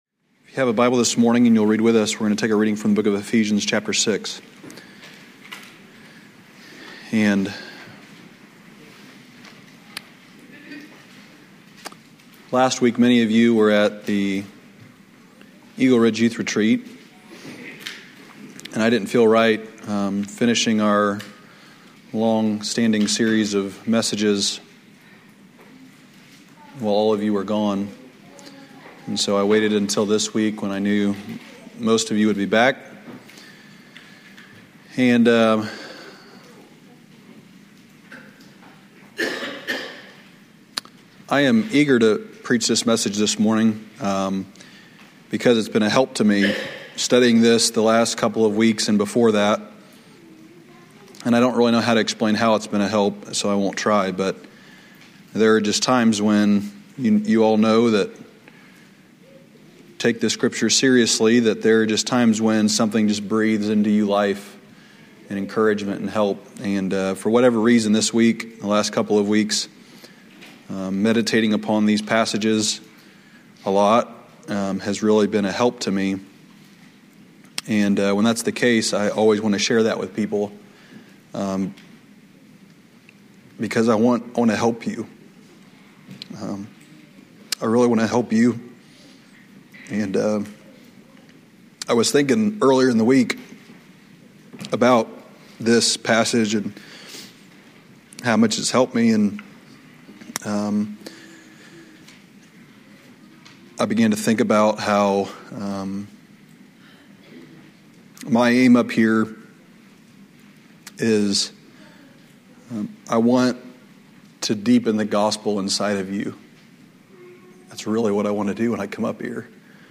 2012 Old Union Ministers School Day 2 Devotional